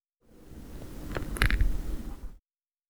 Das Geräusch, welches das Knie meines Mitbewohner produziert, wenn er es beugt, und welches somit über den Zustand des Knies informiert.